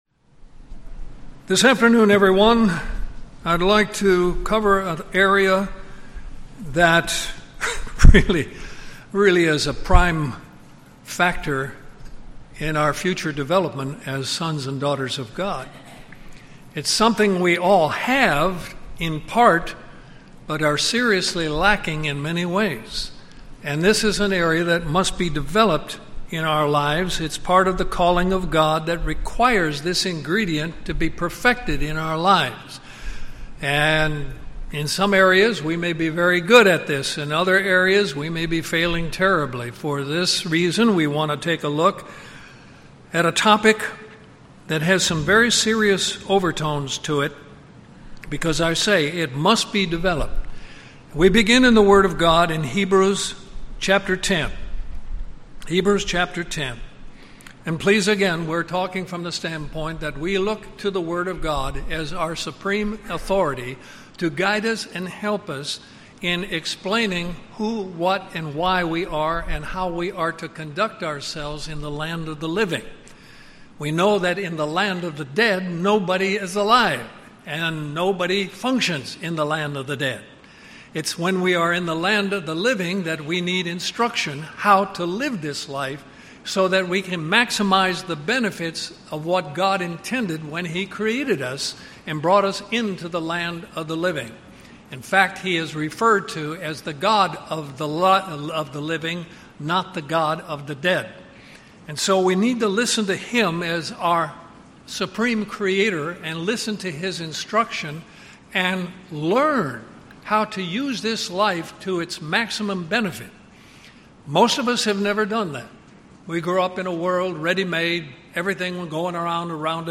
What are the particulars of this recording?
Given in Columbus, GA